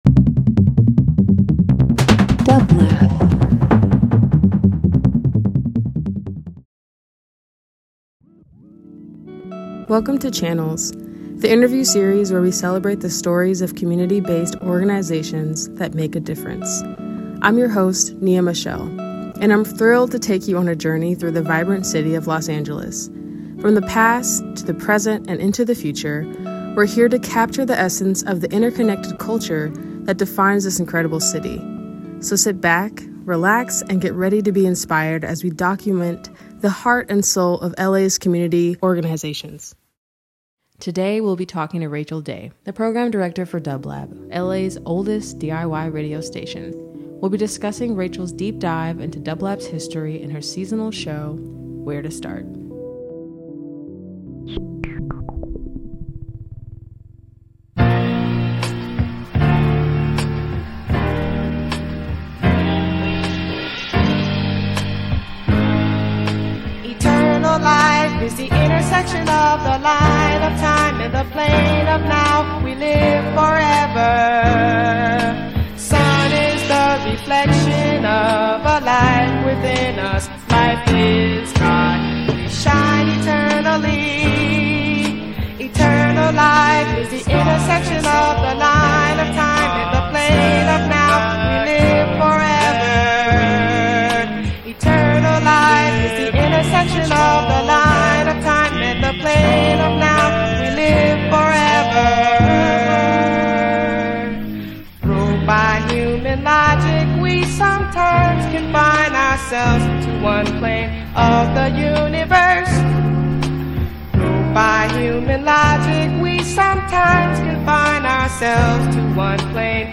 dublab 03.16.23 Avant-Garde Funk/Soul Interview Talk Show For Channel’s debut episode
Featuring a curated mix of music in each episode, the show seeks to connect listeners to a particular place, time, and creative community. Whether it’s independent radio stations, music venues, underground clubs, or other public art spaces, Channels explores the rich cultural tapestry of the world around us.